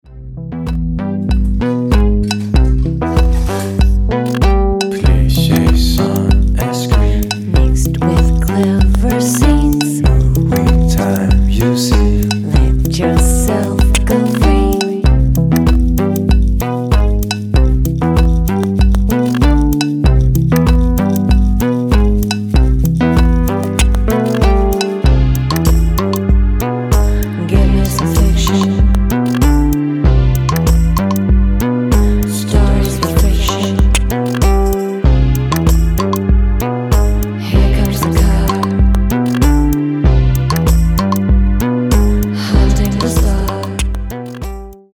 moody, mellow, but freaked out “Spaceship” Electrosongs
acid lines, disco breaks, rave synthesizers, drum machines
sleazy chilled out music